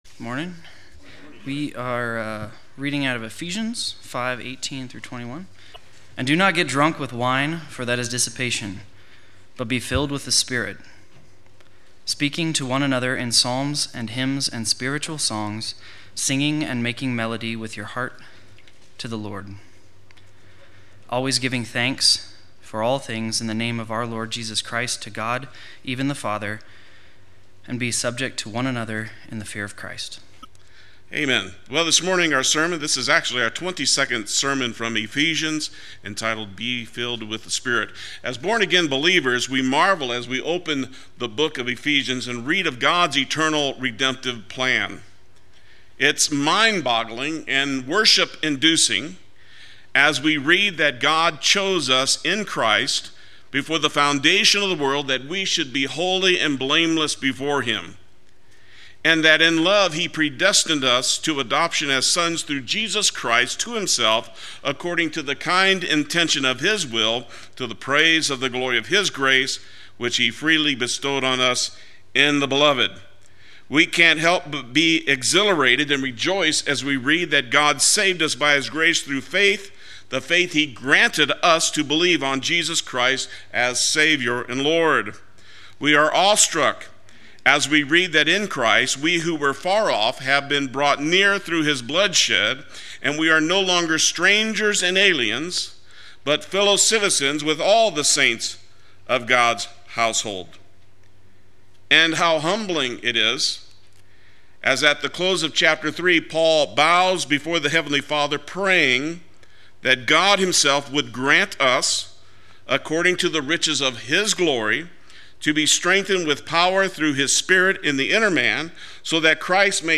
Play Sermon Get HCF Teaching Automatically.
Be Filled With the Spirit Sunday Worship